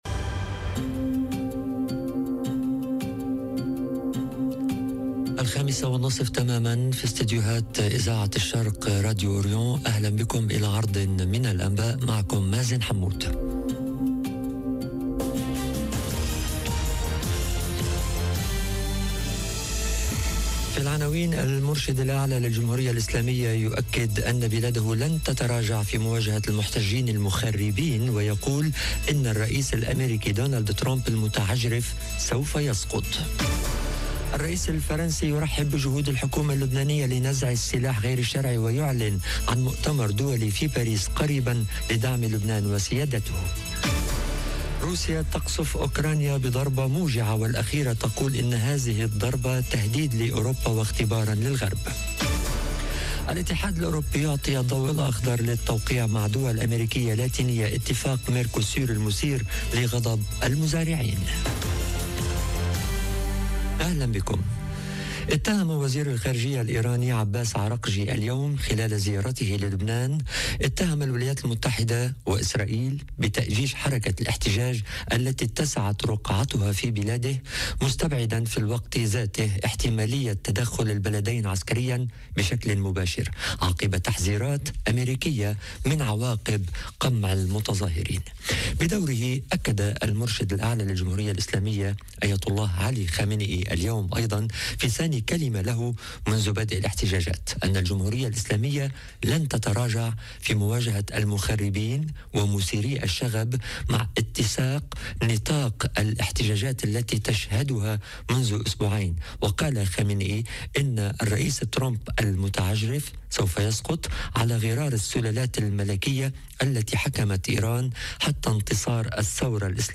نشرة أخبار المساء: -المرشد الأعلى لإيران يؤكد ان بلاده لن تتراجع في مواجهة المحتجين المخربين ويقول ان ترامب المتعجرف سوف يسقط - Radio ORIENT، إذاعة الشرق من باريس